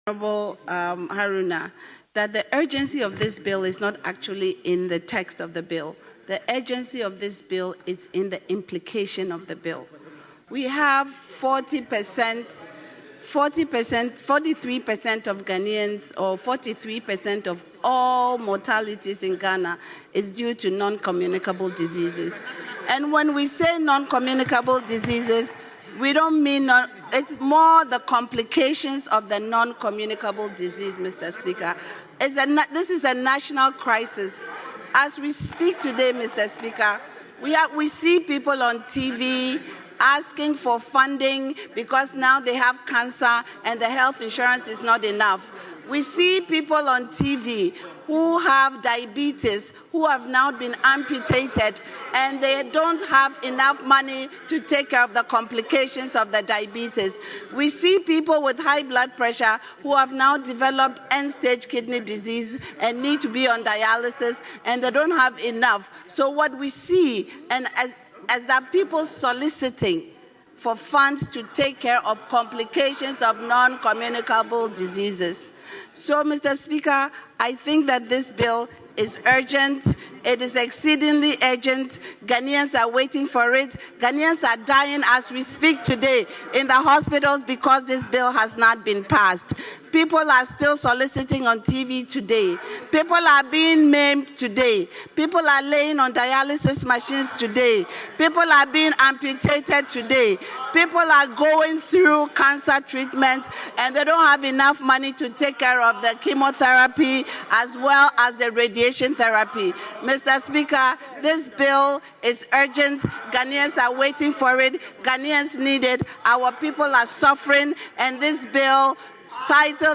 Member of Parliament for Essikado-Ketan constituency, Prof. Dr. Grace Ayensu Danquah, made a passionate appeal on the floor of Parliament for the urgent passage of the Mahama Care Bill, also known as the Ghana Medical Trust Fund Bill.